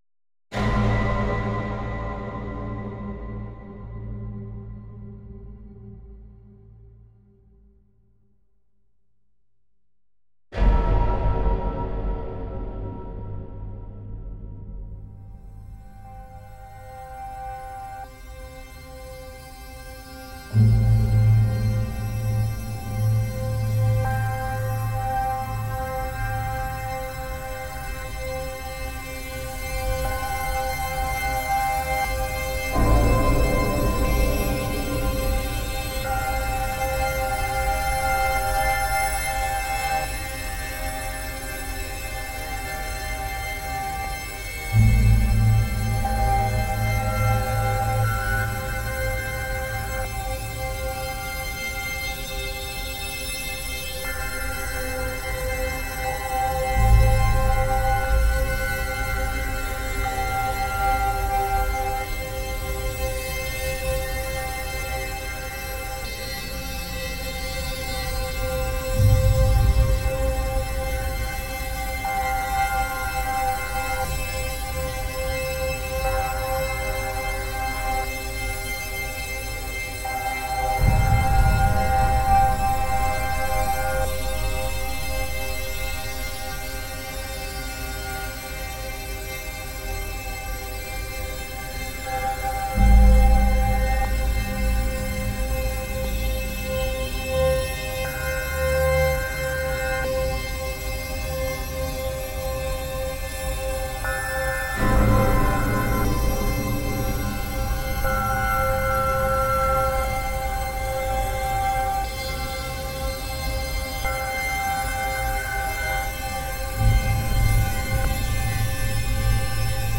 コーランを唱える祈りのような声、のたうち回る歪んだ咆哮、
ある種のジャズやエレクトロニカ、またワールド・ミュージックに近似性を持ち、
エロティックでパワフルで、ホルモン分泌が活発になる感じ。